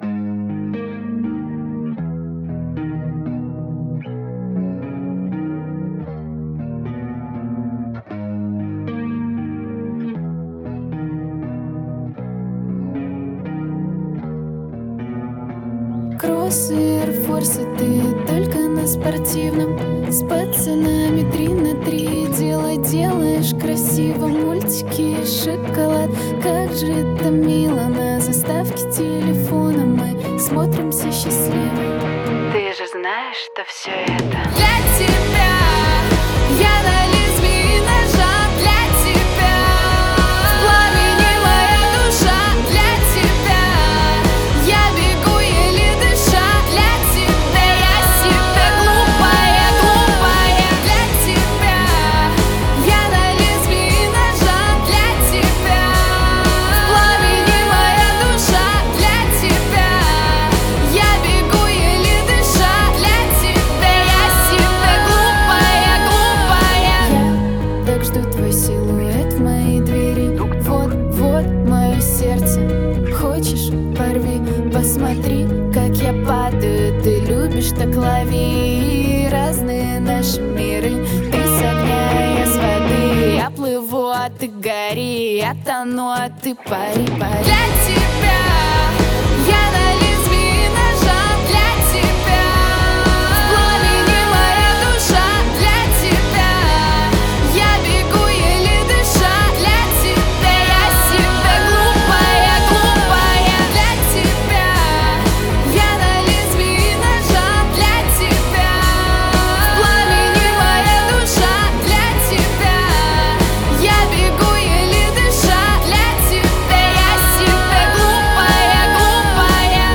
• Жанр: Узбекские песни